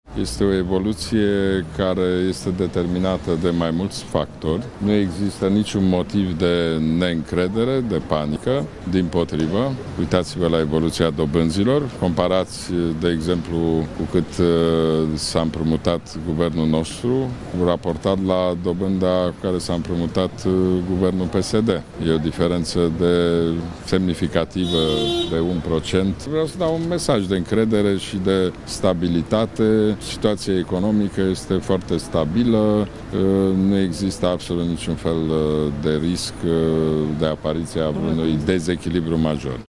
Nu există motive de panică – este reacția premierului Ludovic Orban, după ce euro a atins un nou maxim istoric în raport cu leul.